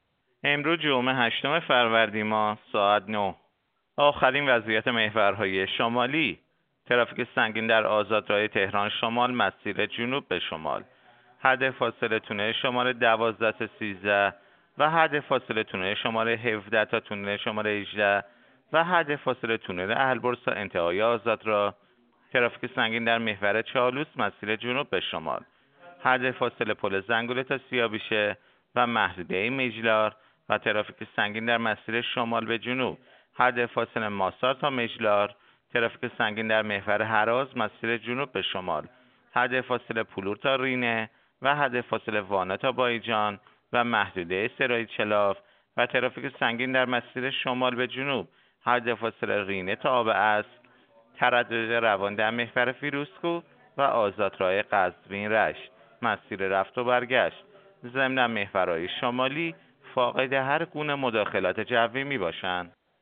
گزارش رادیو اینترنتی از آخرین وضعیت ترافیکی جاده‌ها ساعت ۹ هشتم فروردین؛